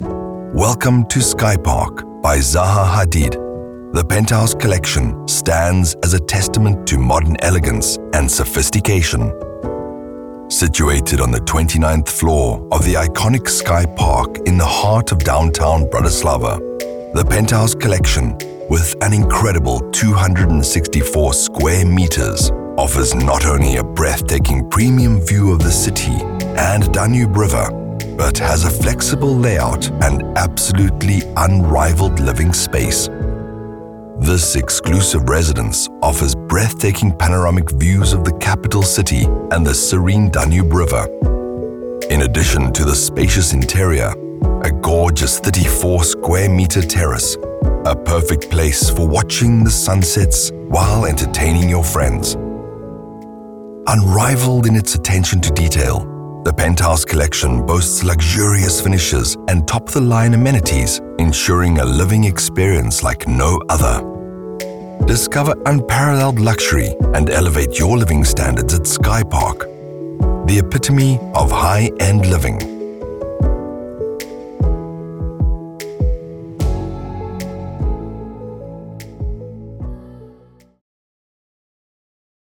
Englisch (südafrikanisch)
Meine freundliche, zugängliche und aufrichtige Stimme ist in Unternehmenserzählungen, Dokumentarerzählungen, Werbespots, E-Learning-Projekten, Videospielen, Cartoons, Anwendungen und Hörbüchern zu hören und zeigt meine große Bandbreite und meine Fähigkeit, mich an verschiedene Genres und Stile anzupassen.
Sennheiser MKH 416, Neumann TLM 103, Universal Audio Volt 276, Adobe Audition, Izotope RX10, Waves NS1